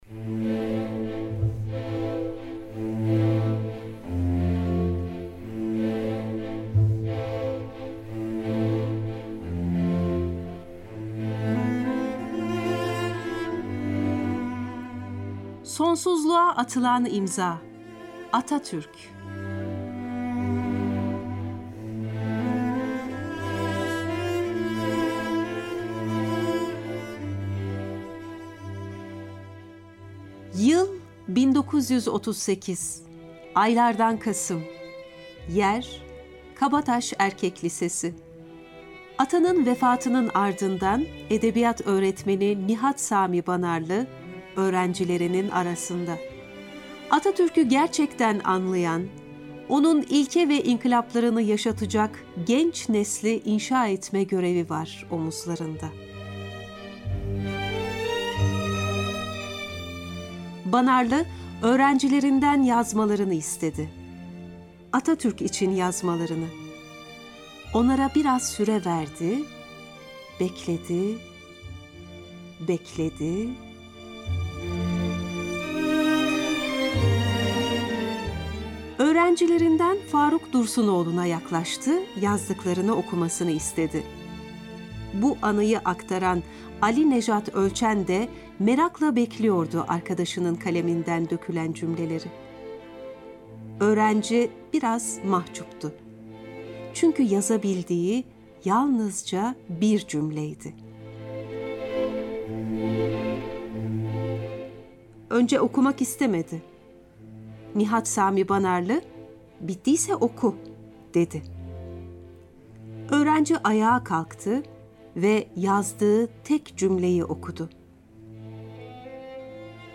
Atatürk'ün fikirlerini biçimlendiren ortama, kaleme aldığı eserlere değiniyoruz. Erken Cumhuriyet döneminde eğitime, bilime, kültüre ve sanata verdiği önemi gösteren anılarına yer veriyoruz. Zengin TRT arşivindeki Atatürk anılarını sahiplerinin sesinden dinliyoruz.